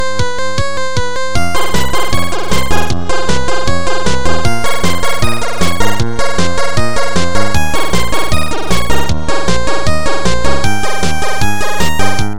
без слов
8-бит
электроника